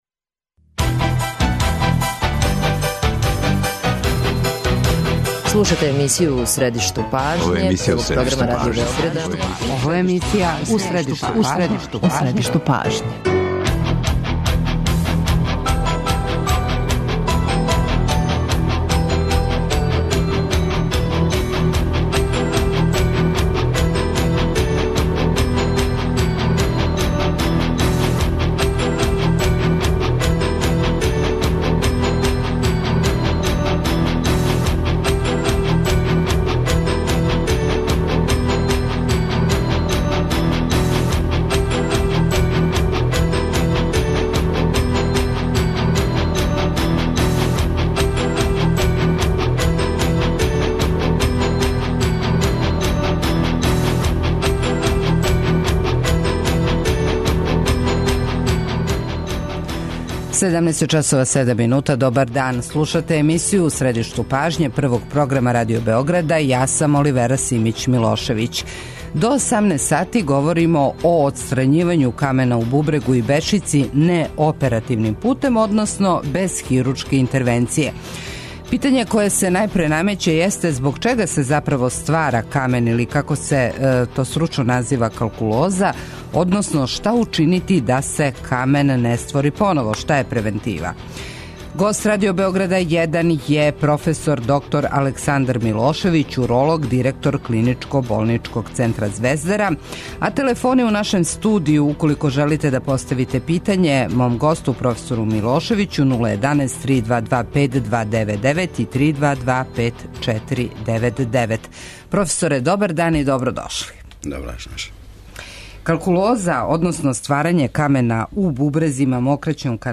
доноси интервју